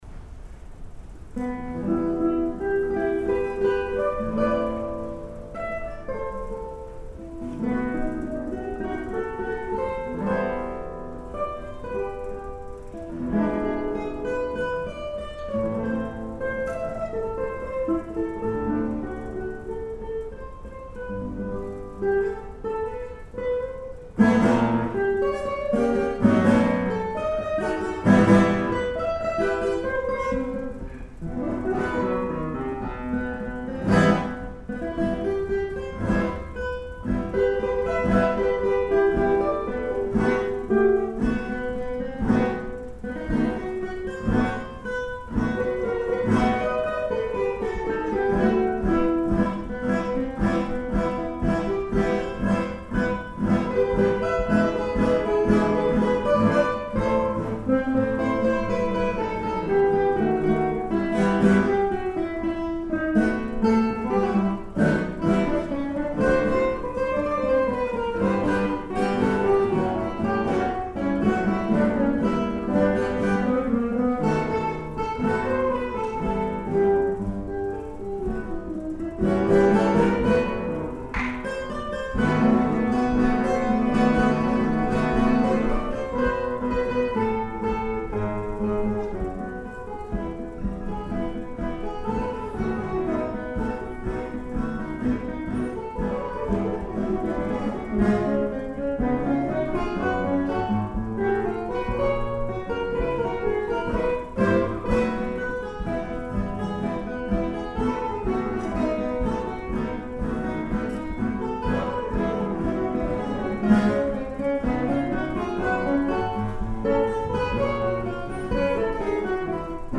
ギターコンサート
ensemble